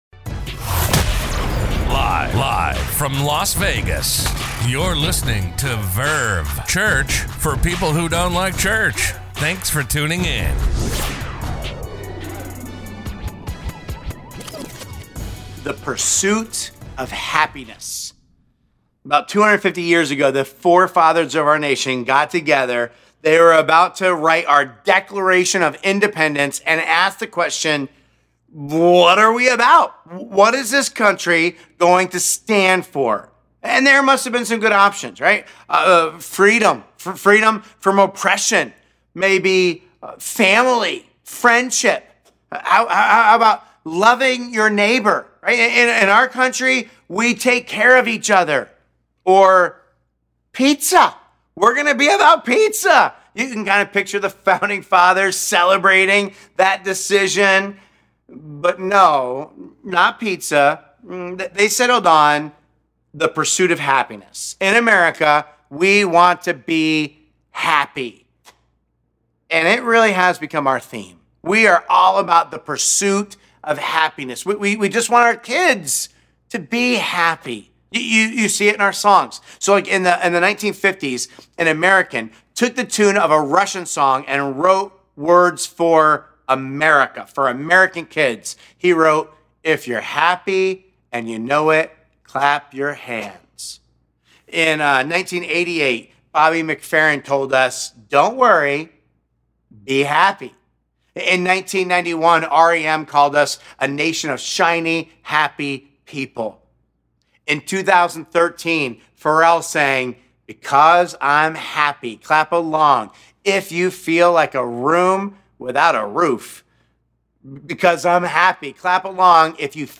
A message from the series "Chasing Happy.." Our past can hold us back from happiness. How do you move past your past?